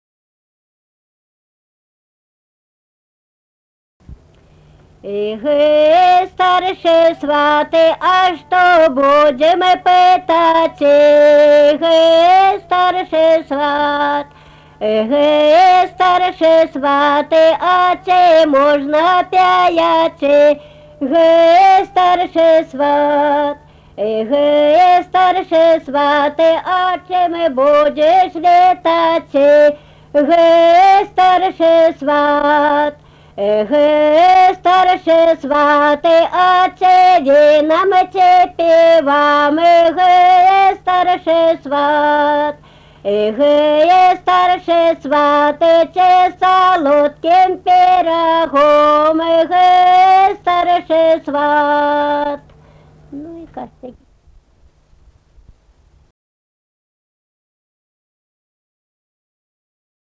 Kavaltiškė, Kavoliškės k.
Atlikimo pubūdis vokalinis
Baltarusiška daina